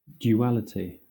Ääntäminen
Southern England
IPA : /d(j)uːˈælɪti/